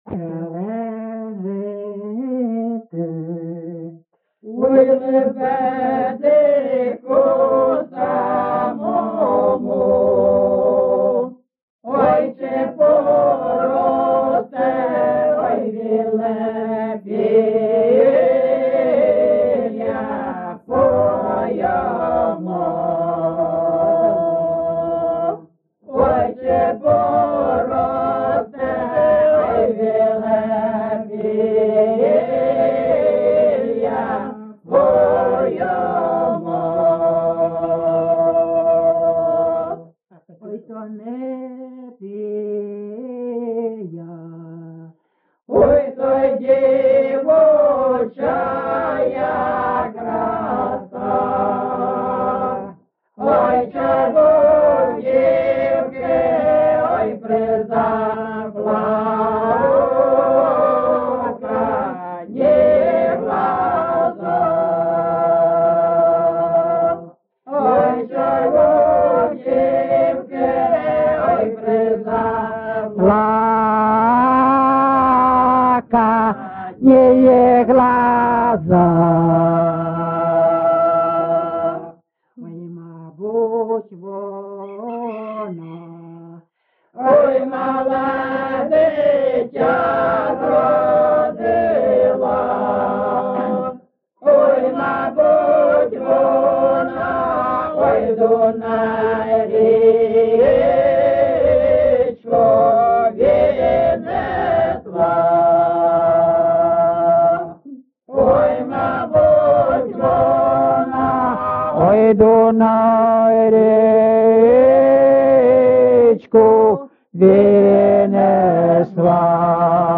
GenrePersonal and Family Life
Recording locationLyman, Zmiivskyi (Chuhuivskyi) District, Kharkiv obl., Ukraine, Sloboda Ukraine